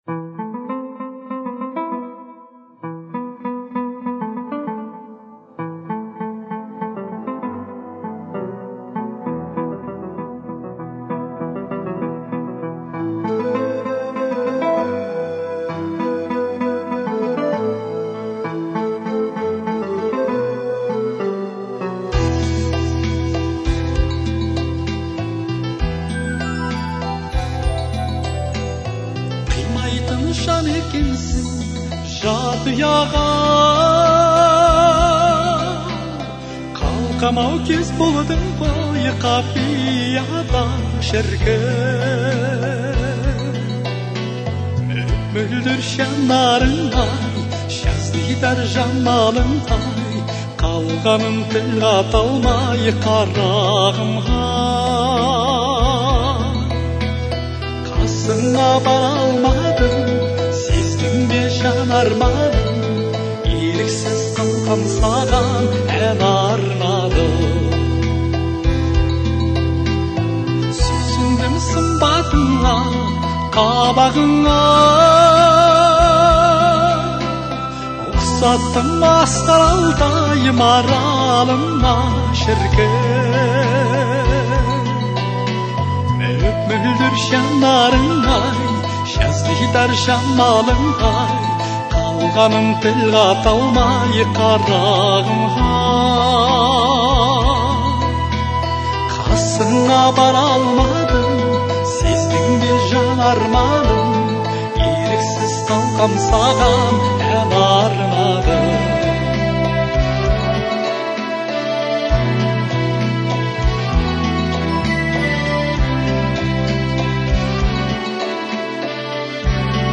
это песня в жанре казахского народного фольклора